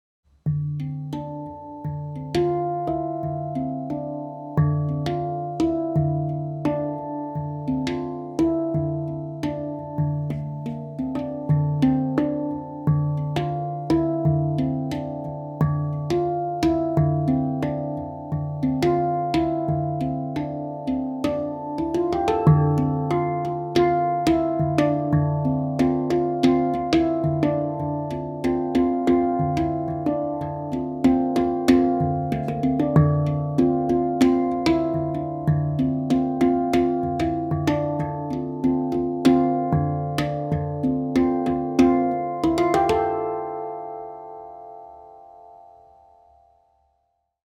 Moon II Handpan D-Integral / Kurd
Kurd/Integral gir et varmt, harmonisk og balansert lydbilde som oppleves intuitivt og enkelt å spille på.
Instrumentet er laget av rustfritt stål, noe som gir en klar tone, lang sustain og balanserte overtoner.
D3, A3, Bb3, C4, D4, E4, F4, G4, A4
• Frekvens: 440 Hz